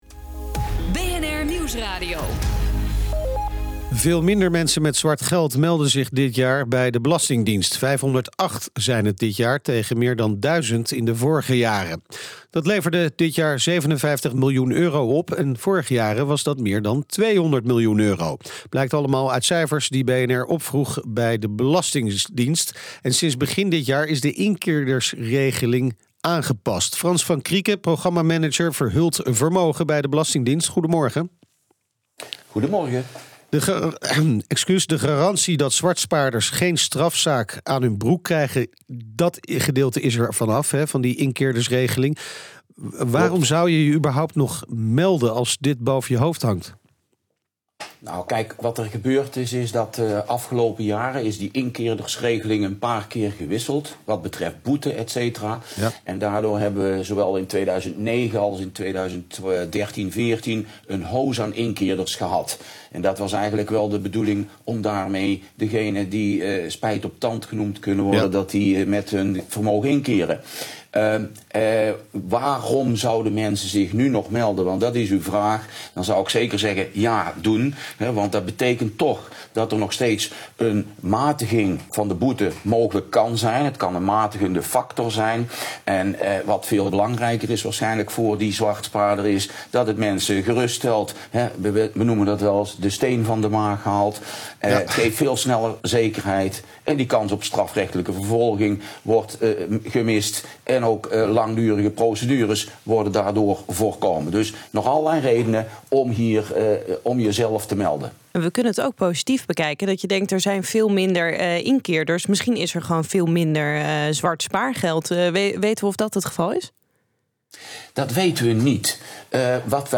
Transcriptie BNR gesprek met Belastingdienst/Verhuld Vermogen